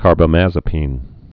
(kärbə-măzə-pēn)